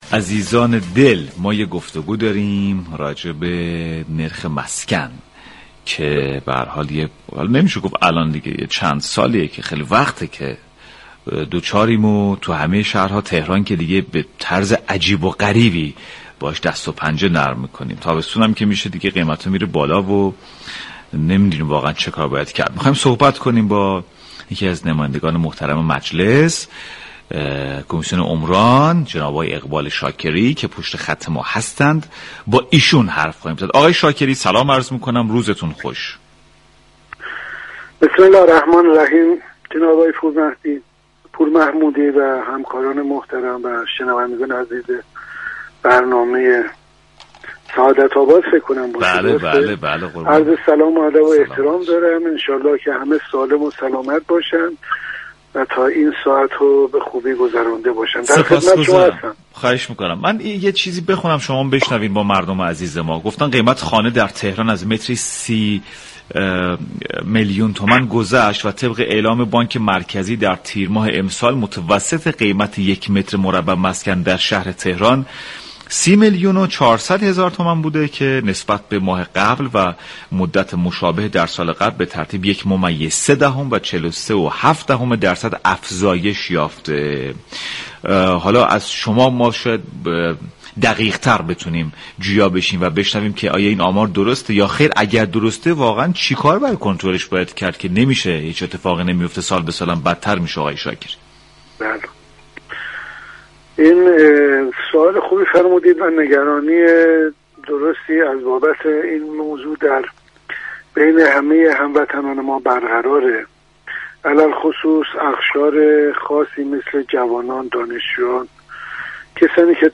اقبال شاكری عضو كمیسیون عمران مجلس شورای اسلامی در گفتگو با برنامه سعادت آباد رادیو تهران در پاسخ به اظهارات